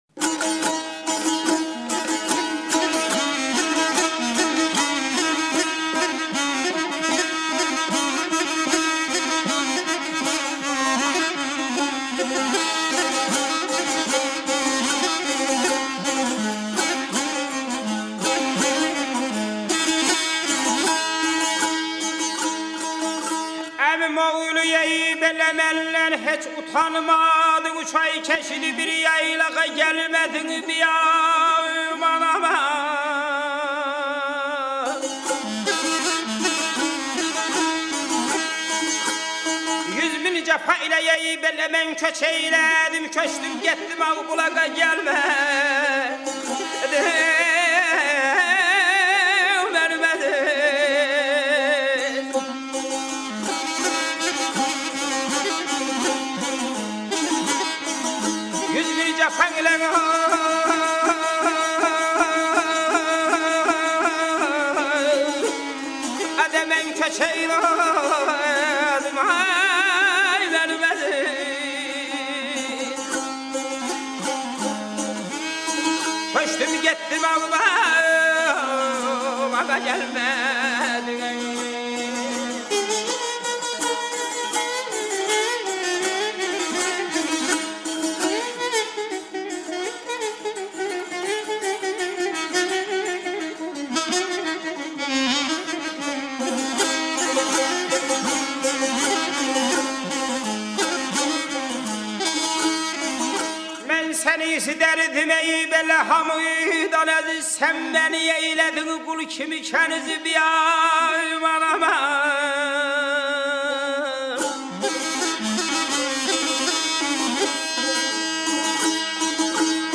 У. Ашик – это восточный поэт, исполняющий свои песнопения под звуки сааза (саза).
- Послушайте современную аудиозапись пения ашика и рассмотрите азербайджанские народные костюмы.